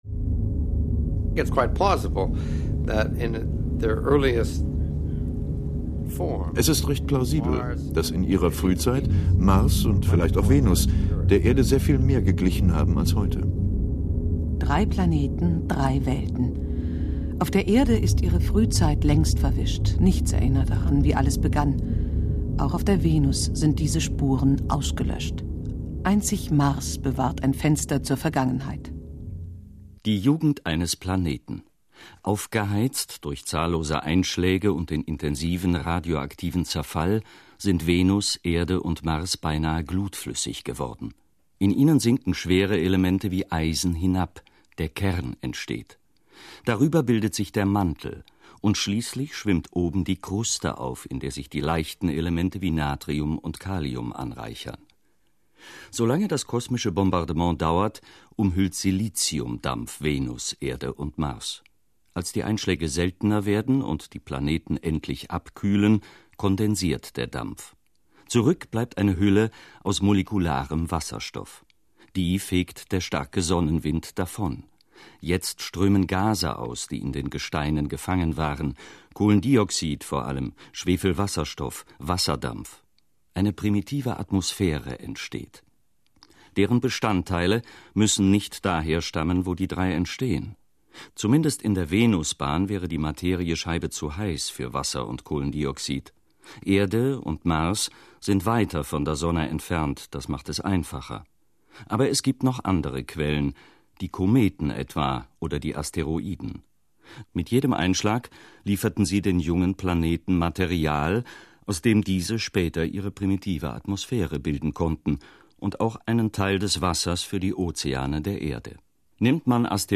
Ein Feature von